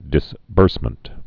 (dĭs-bûrsmənt)